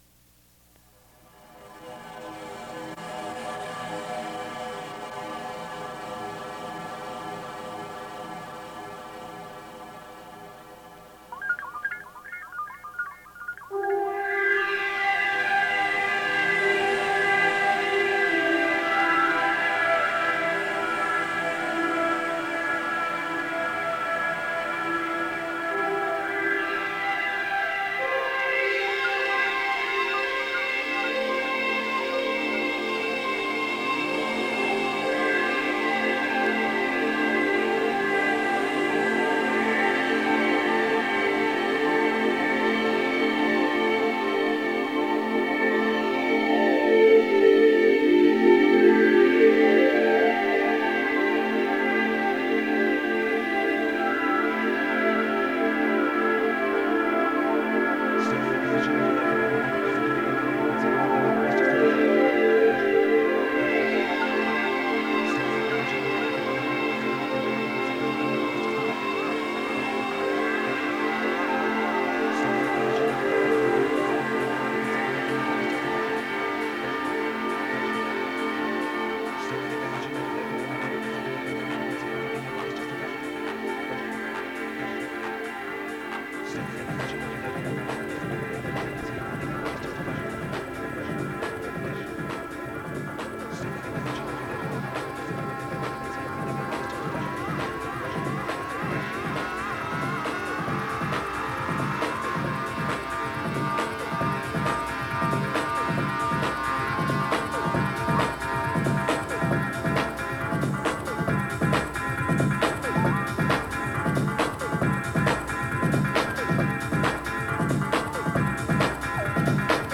...Live on WRPI 1994 download: ▼